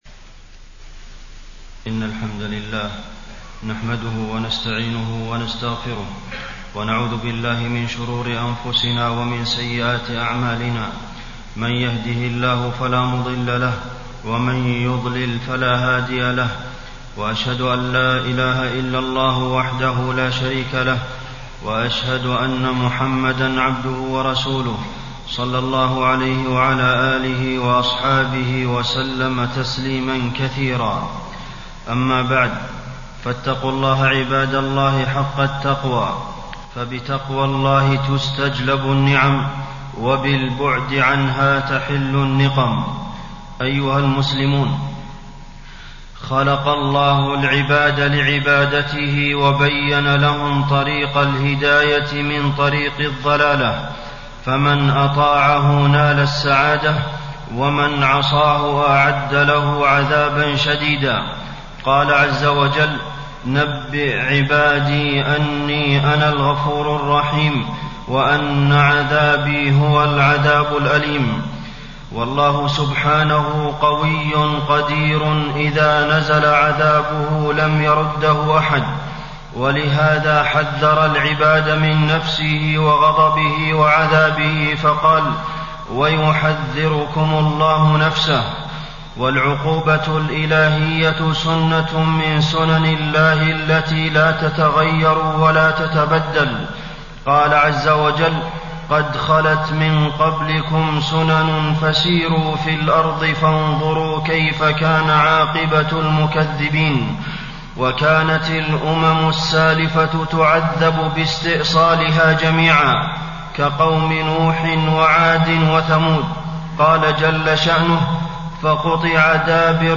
تاريخ النشر ٢١ جمادى الأولى ١٤٣٣ هـ المكان: المسجد النبوي الشيخ: فضيلة الشيخ د. عبدالمحسن بن محمد القاسم فضيلة الشيخ د. عبدالمحسن بن محمد القاسم العقوبات الإلهية سنة ماضية